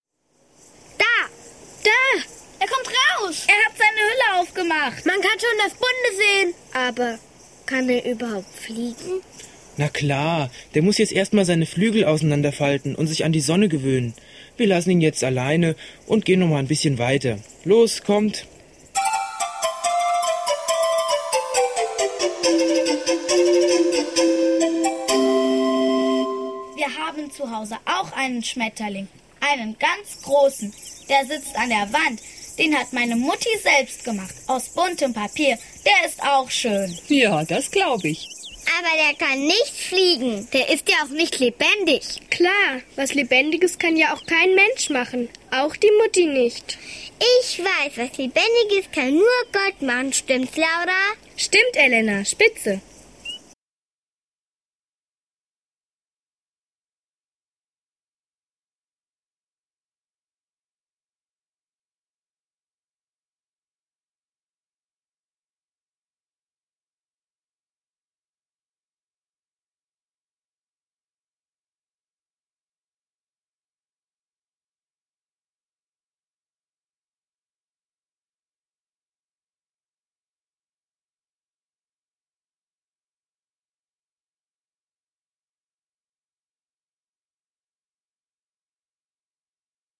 6. Ein Papierschmetterling - Hörszene 3